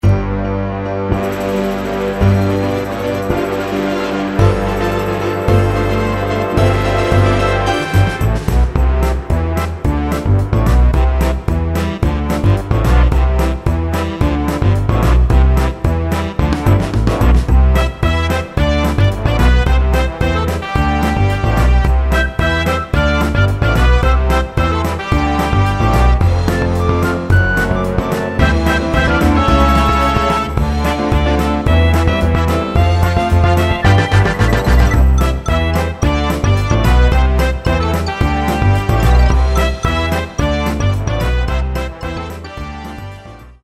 An original composition to open a pantomime